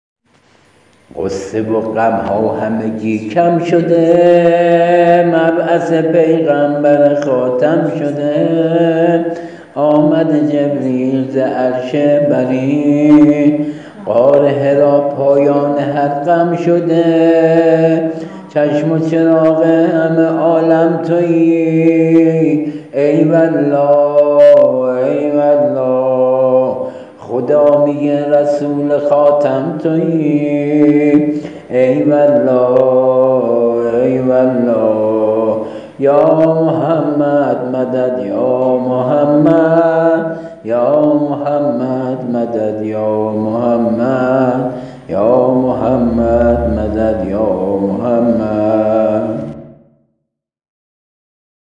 با ملودی جدید اثر شاعر